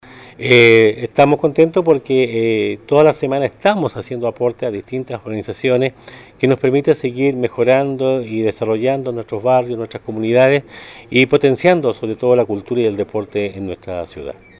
Así lo destacó el alcalde Emeterio Carrillo, quien señaló que estas organizaciones desarrollan actividades en el ámbito de la salud, cultural y deportivo, y a quienes se les entregó un monto cercano a los 15 millones de pesos